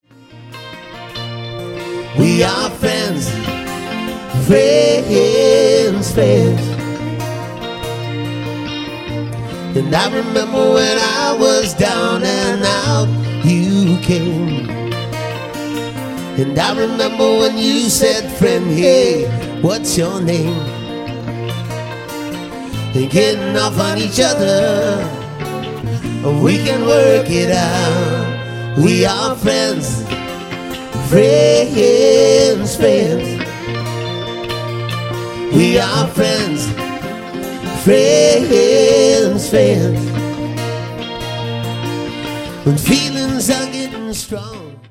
Genre: Island contemporary.